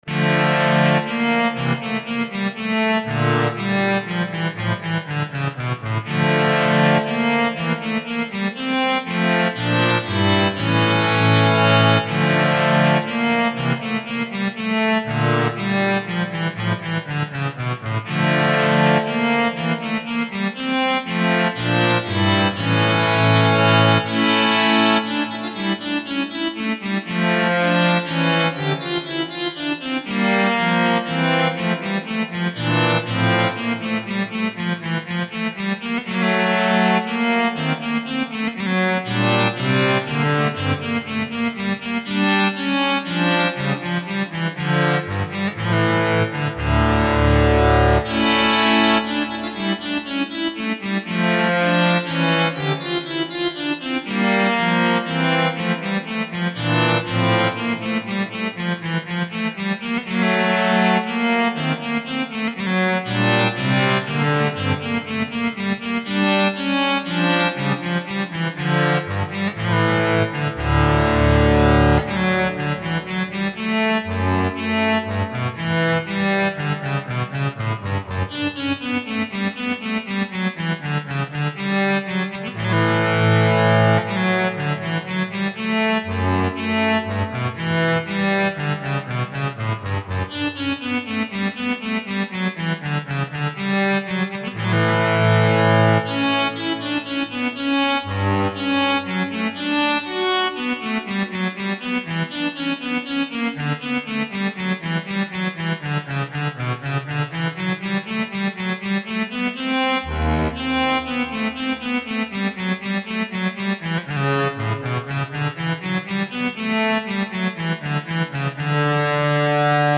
Bach Synthesizer